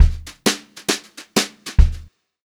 200JZFILL1-L.wav